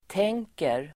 Uttal: [t'eng:ker]